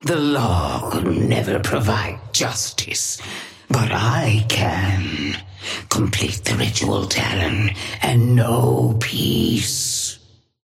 Sapphire Flame voice line - The law could never provide justice, but I can. Complete the ritual, Talon, and know peace.
Patron_female_ally_orion_start_09.mp3